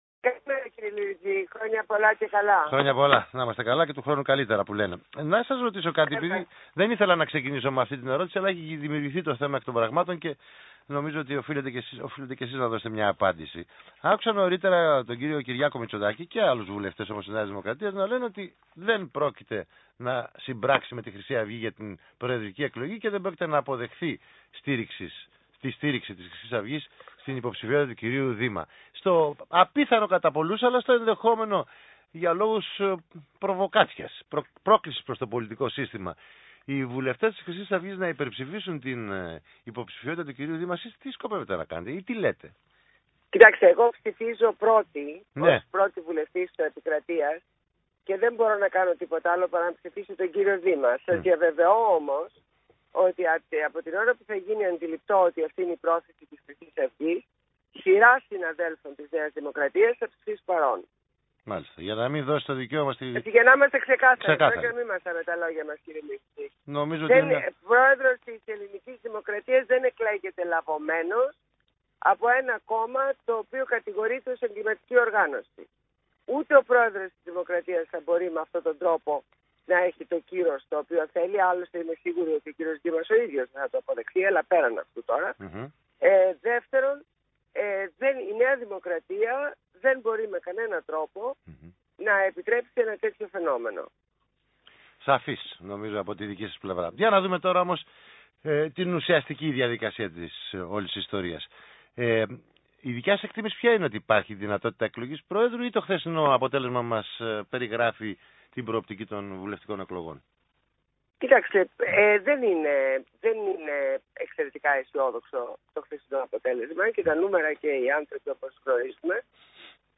Συνέντευξη στο ραδιόφωνο του ΣΚΑΪ 100.3